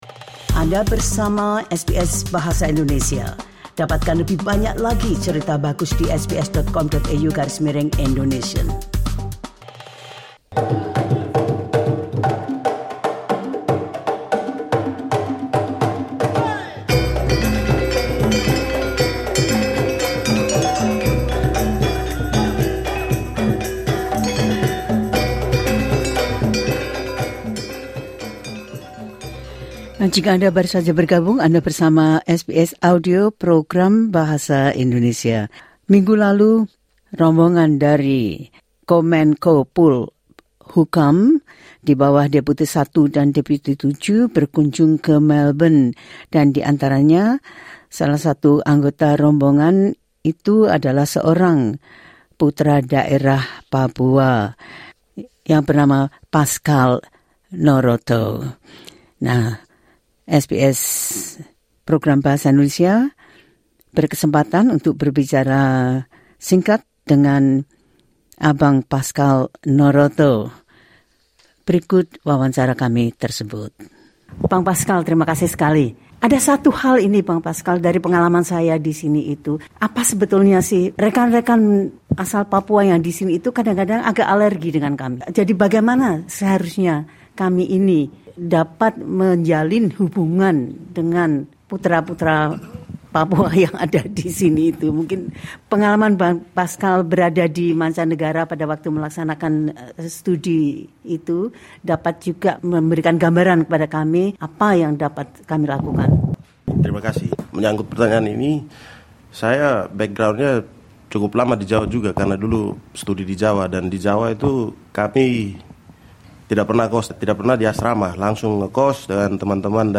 Dalam pembicaraan singkat dengan SBS Indonesian di Melbourne